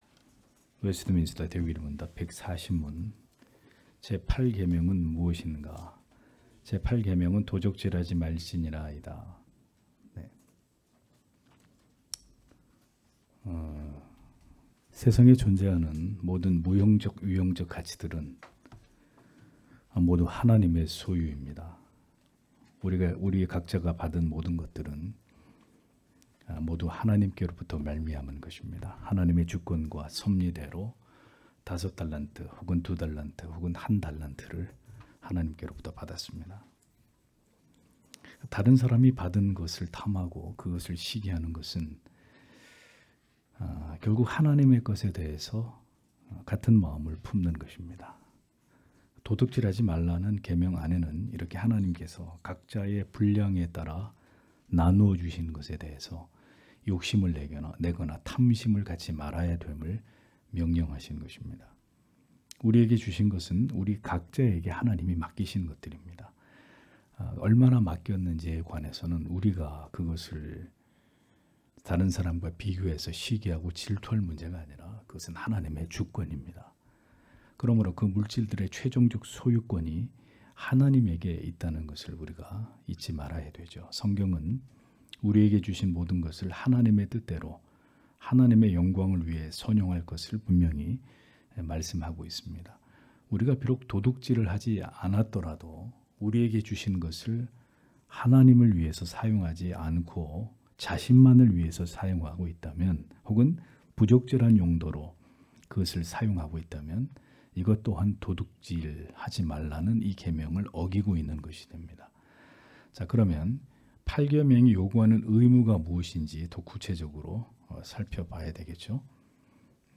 주일오후예배 - [웨스트민스터 대요리문답 해설 140-141] 140문) 제 8계명은 무엇인가? 141문) 제 8계명에 요구된 의무는 무엇인가 (출 20장 15절)
* 설교 파일을 다운 받으시려면 아래 설교 제목을 클릭해서 다운 받으시면 됩니다.